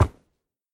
wood5.mp3